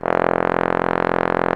Index of /90_sSampleCDs/Roland L-CDX-03 Disk 2/BRS_Bs.Trombones/BRS_Bs.Bone Solo